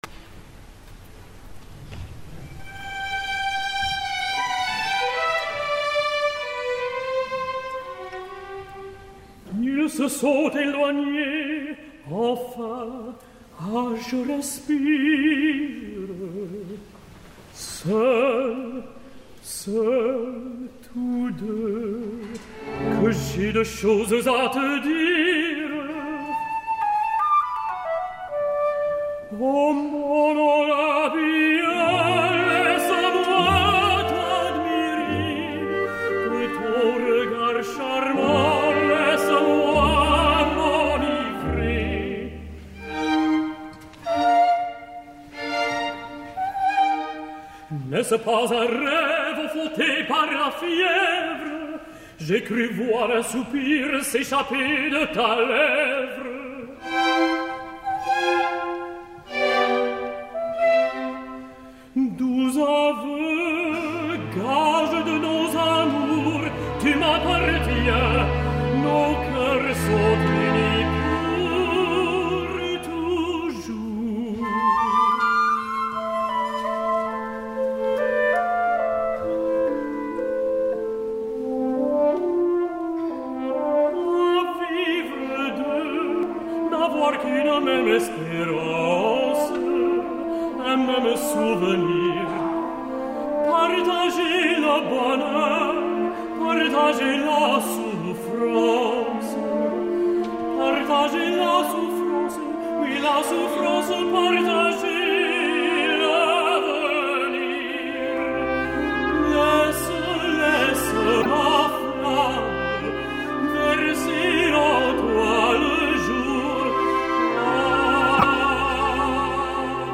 El Hoffmann del tenor John Osborn, a qui potser li manca una mica més de gruix, ho compensa amb una línia excel·lent i una adequada elegància estilística.
Versió de concert
John Osborn, tenor (Hoffmann)
22 de novembre de 2012, Salle Pleyel de Paris